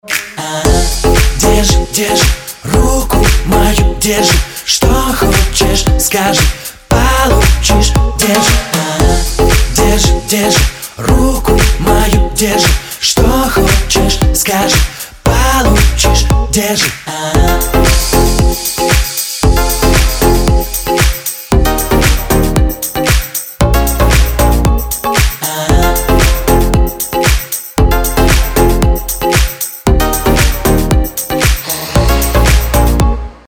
• Качество: 192, Stereo
поп
dance